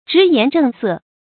直言正色 注音： ㄓㄧˊ ㄧㄢˊ ㄓㄥˋ ㄙㄜˋ 讀音讀法： 意思解釋： 謂言語正直，儀容嚴肅。